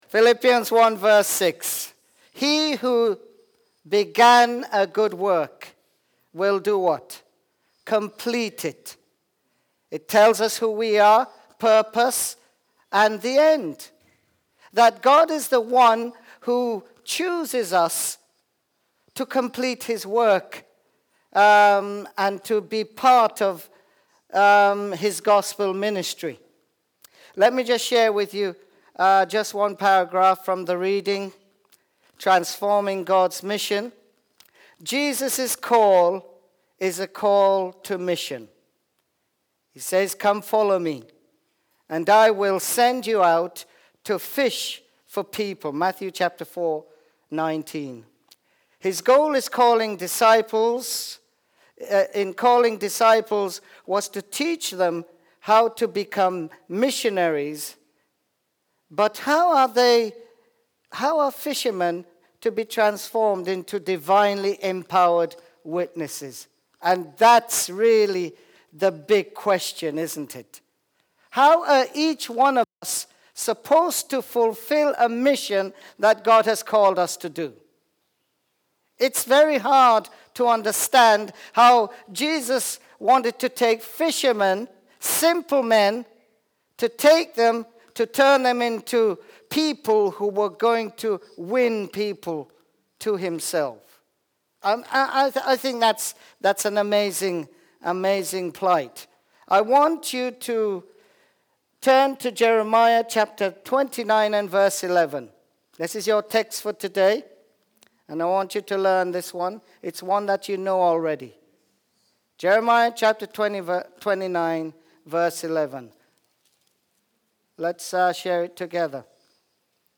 This message was preached during our week of prayer in 2016.